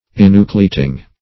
enucleating - definition of enucleating - synonyms, pronunciation, spelling from Free Dictionary